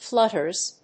発音記号
• / ˈflʌtɝz(米国英語)
• / ˈflʌtɜ:z(英国英語)
flutters.mp3